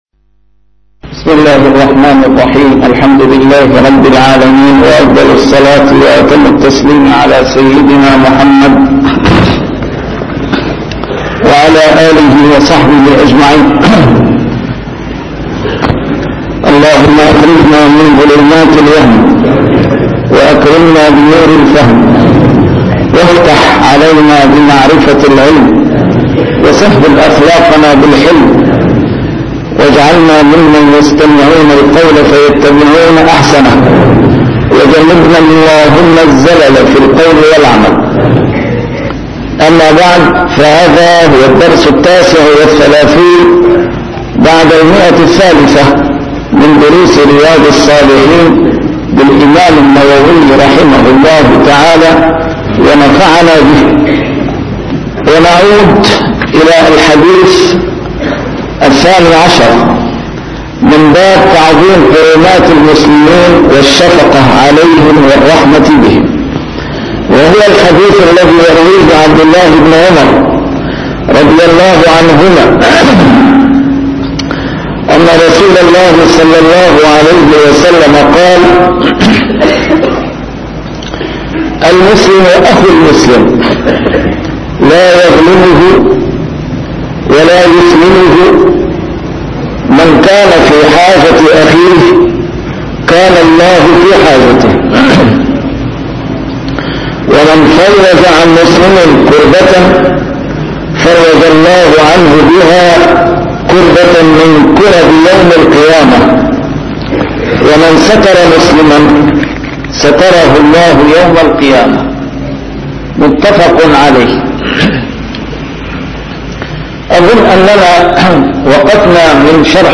A MARTYR SCHOLAR: IMAM MUHAMMAD SAEED RAMADAN AL-BOUTI - الدروس العلمية - شرح كتاب رياض الصالحين - 339- شرح رياض الصالحين: تعظيم حرمات المسلمين